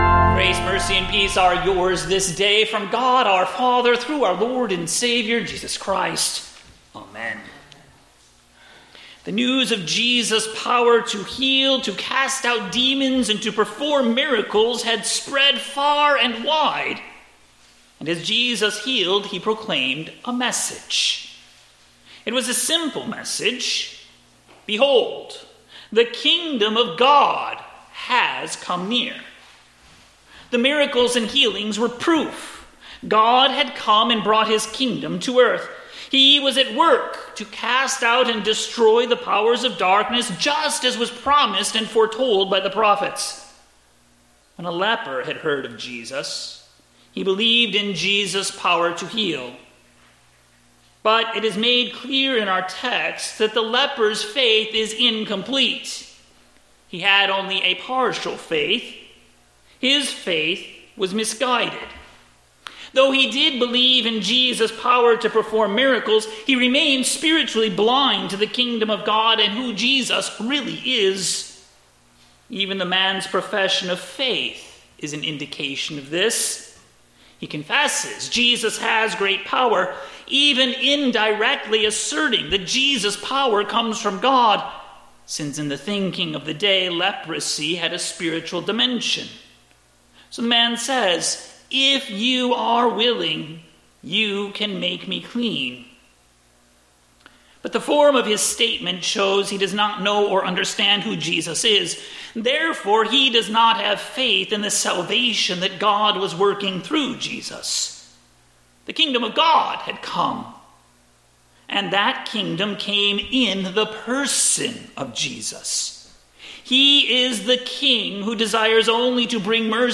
WedSermon-2023-09-13.mp3